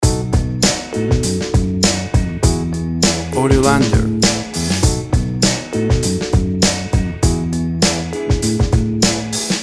Fifth version, (loop) extracted from the first.
WAV Sample Rate 24-Bit Stereo, 44.1 kHz
Tempo (BPM) 100